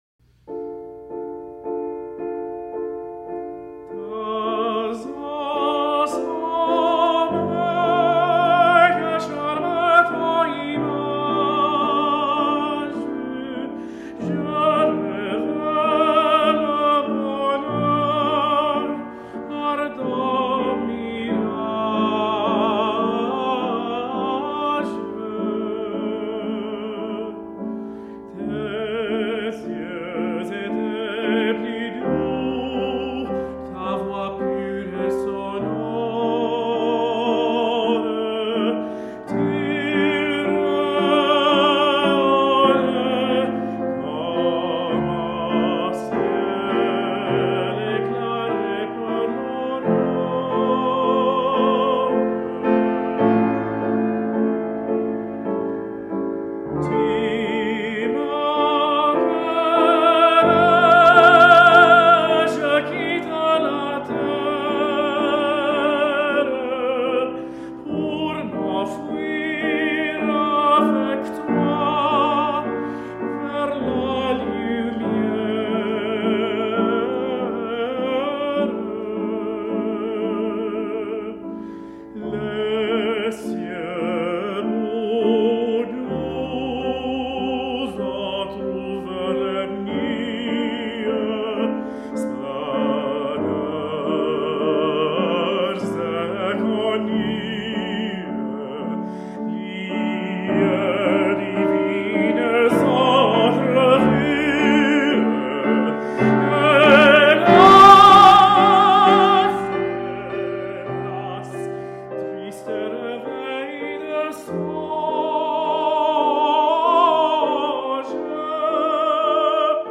Saint Lucian born lyric tenor has died.
Los Angels recordings